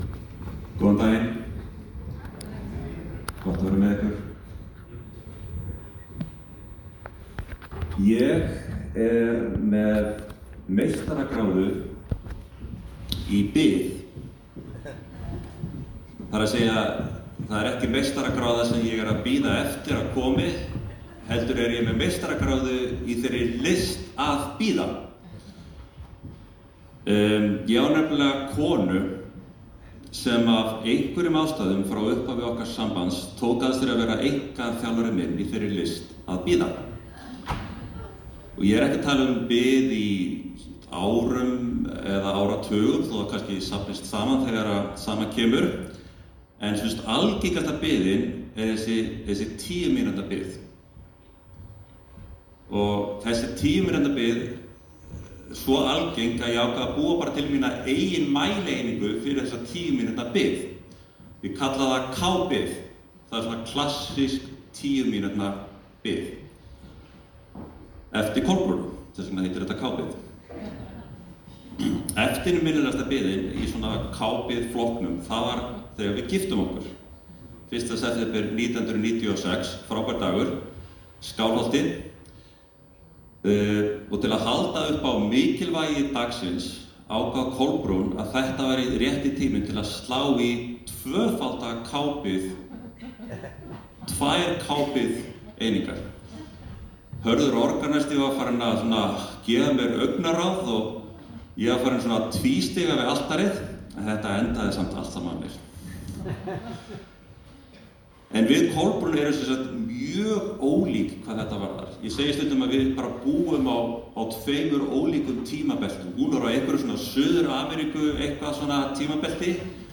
Aðventupredikun í Fíladelfíu Reykjavík 30.nóv 2025.